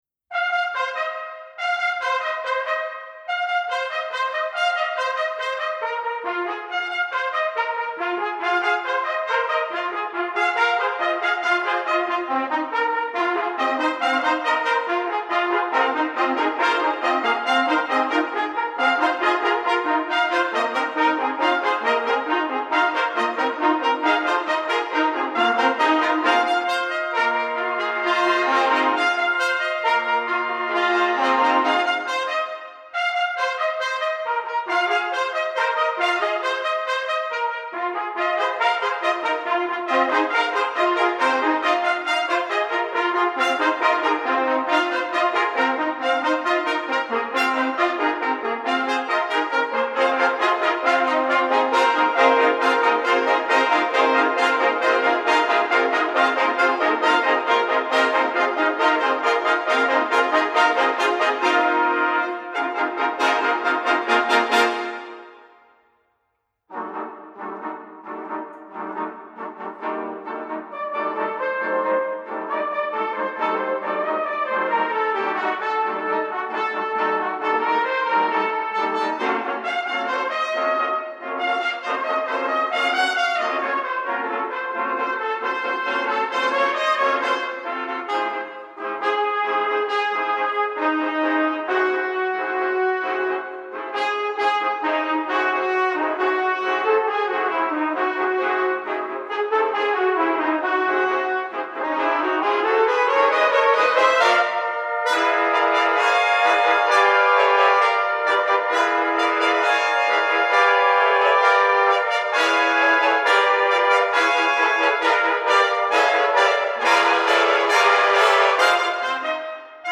Trumpet Ensemble
James Barnes—Toccata: for 4 Trumpets, 4 Cornets, and 4 Flugelhorns